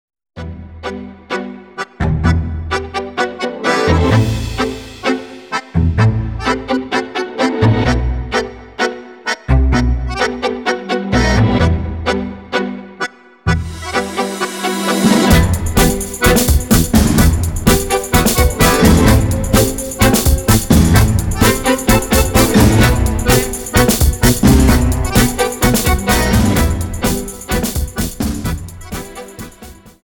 Tango 32 Song